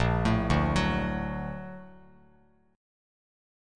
A#+M7 chord